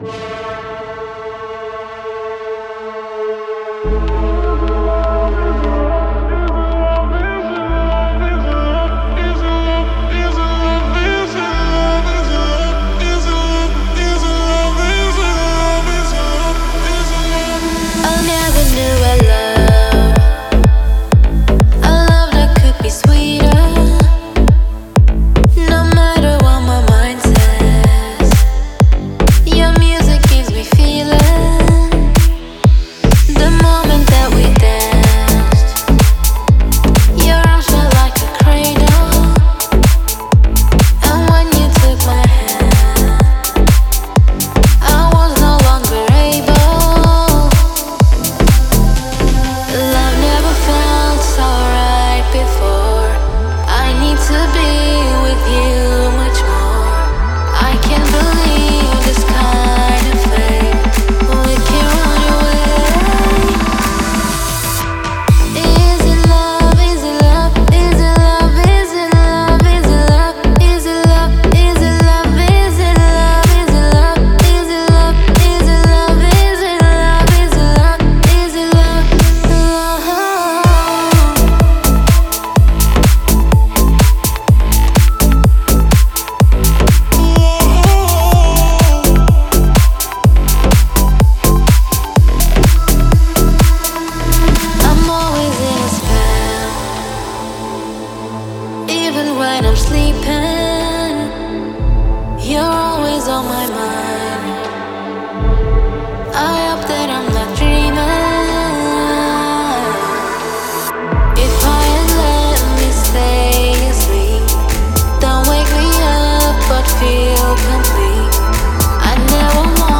это романтичная поп-баллада